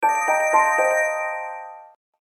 15_Pause_tone.ogg